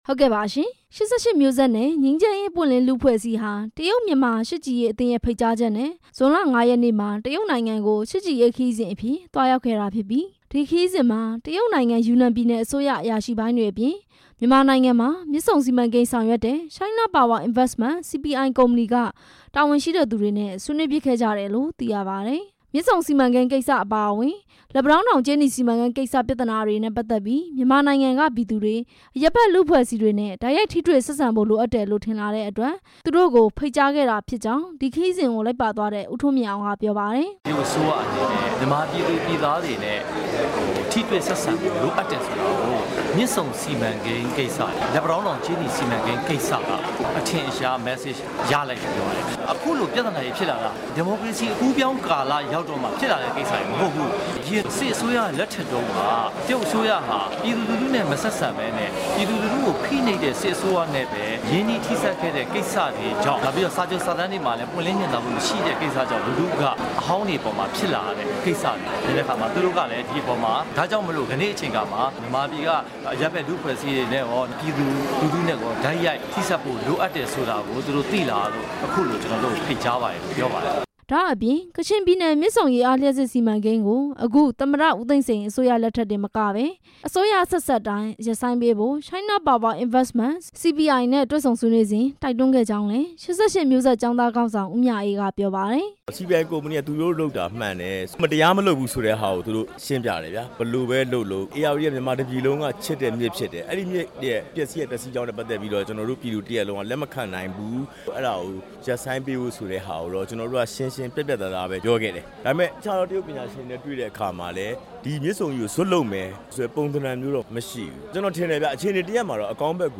၈၈ မျိုးဆက်ကျောင်းသားတွေရဲ့ တရုတ်ခရီးစဉ် တင်ပြချက်